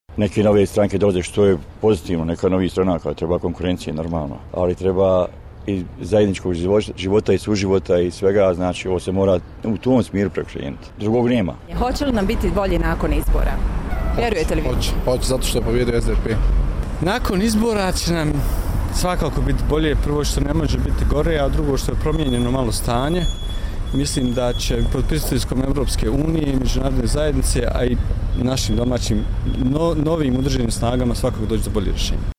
Anketa: Građani Sarajevo o izbornim rezultatima